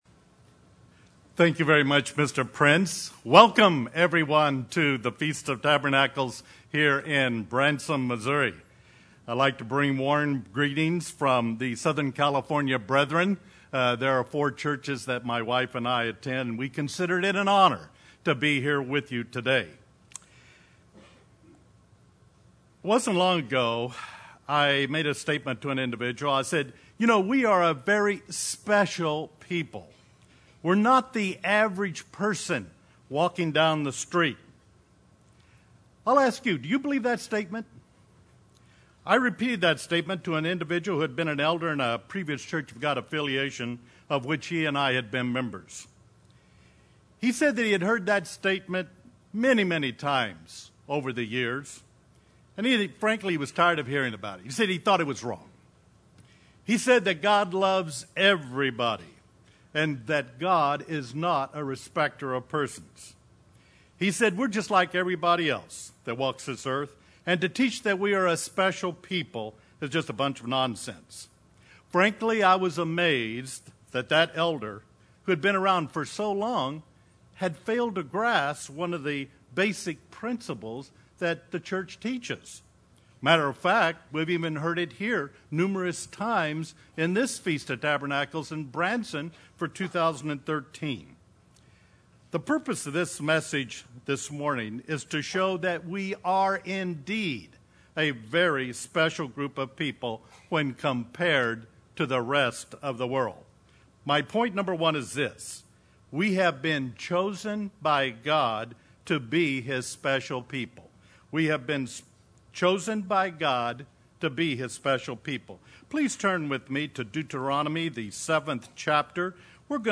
This sermon was given at the Branson, Missouri 2013 Feast site.